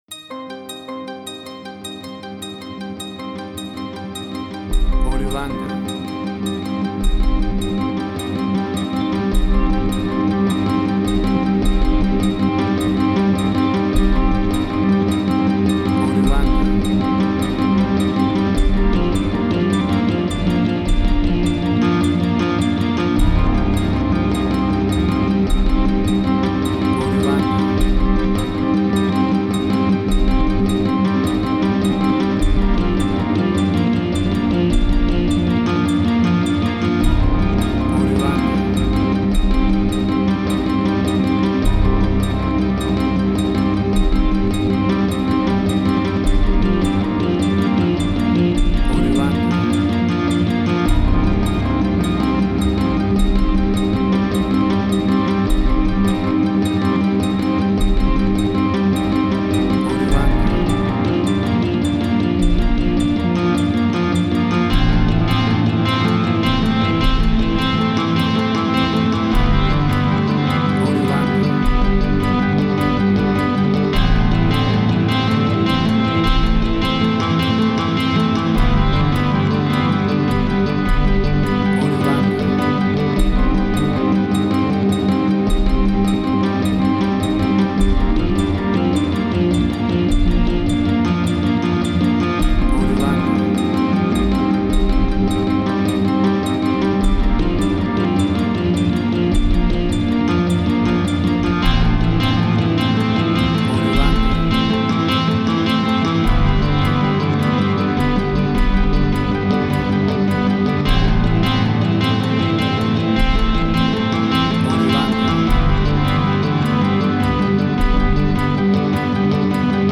Post-Electronic.
Tempo (BPM): 103